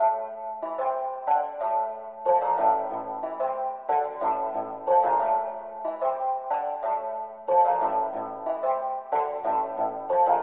Tag: 92 bpm Ethnic Loops Synth Loops 1.76 MB wav Key : Unknown